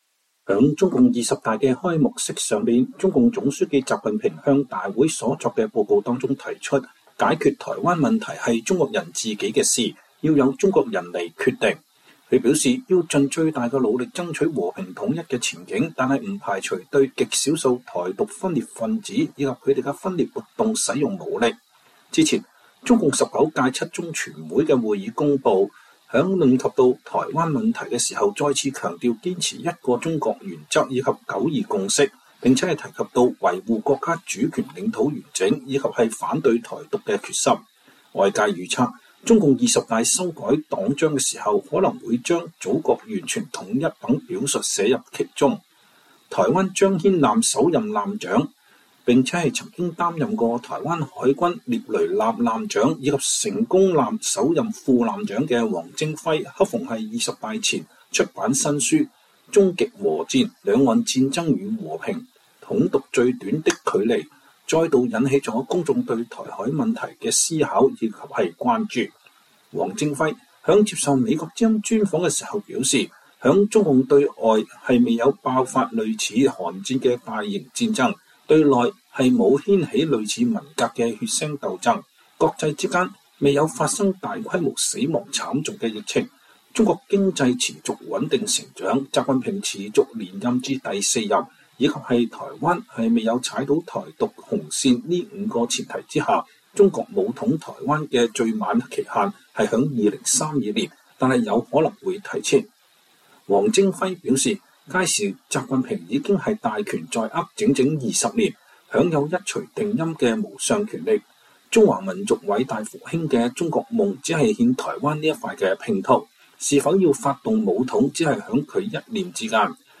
專訪台灣前艦長：五個前提下中共武統台灣最晚期限為2032年